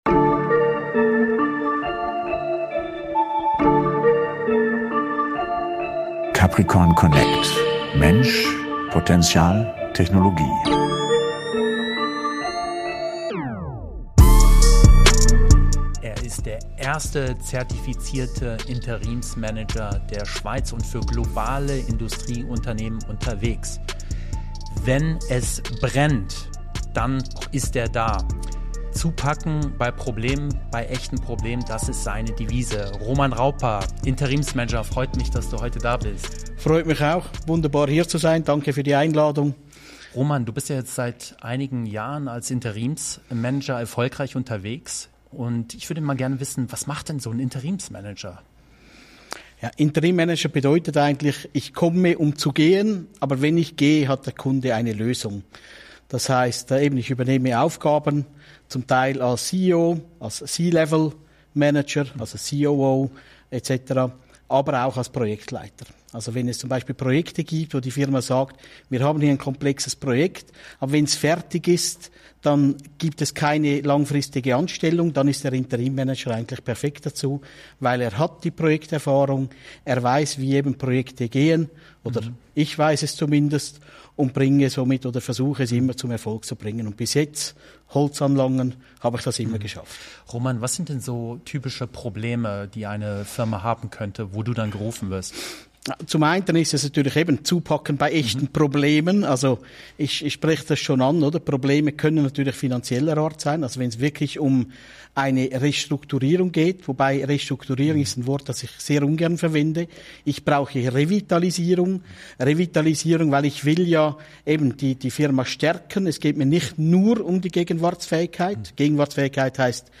Ein Gespräch voller Erfahrung, Pragmatismus und Klarheit.